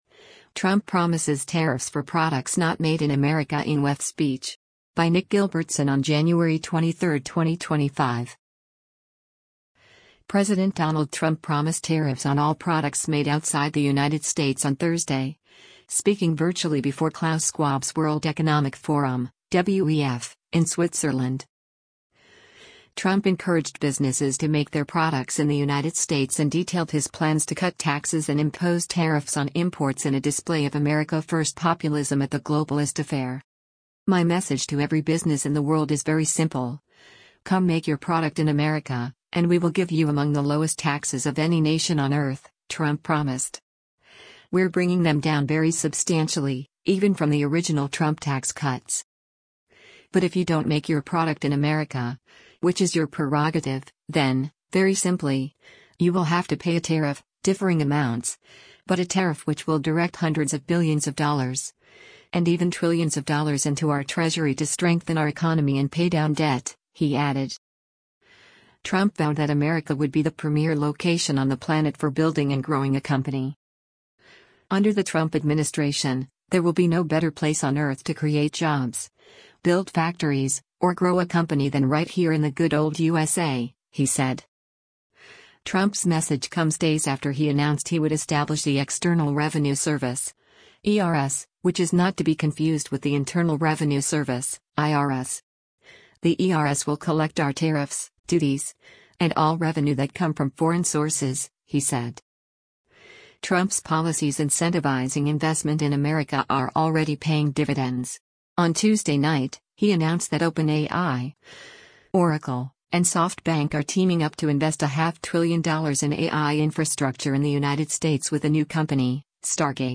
President Donald Trump promised tariffs on all products made outside the United States on Thursday, speaking virtually before Klaus Scwhab’s World Economic Forum (WEF) in Switzerland.